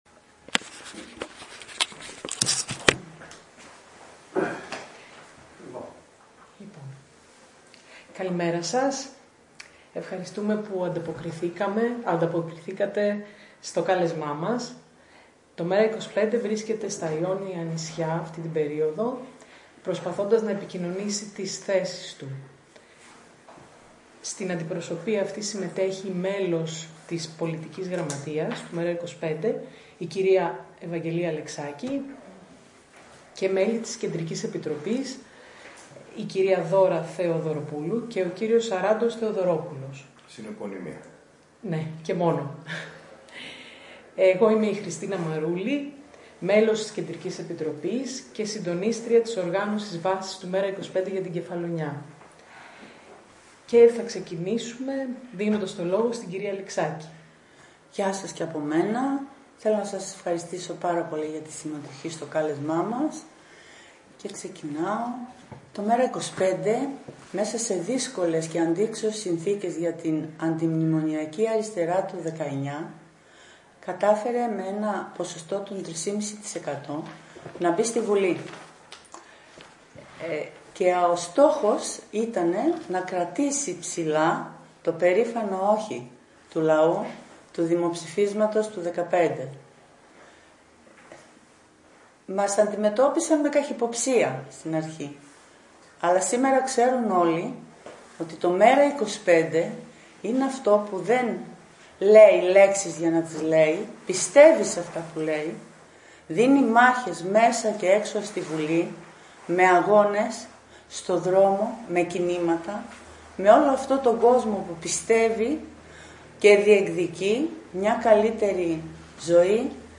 Συνέντευξη τύπου παραχώρησαν στο Ξενοδ. MIRABEL τα μέλη της Πολιτικής Γραμματείας και της Κεντρικής Επιτροπής του ΜέΡΑ 25 με θέμα τις πολιτικές θέσεις και προτάσεις του ΜέΡΑ 25.
Οι ομιλίες ηχογραφήθηκαν όλες στο video υπάρχουν αποσπάσματα.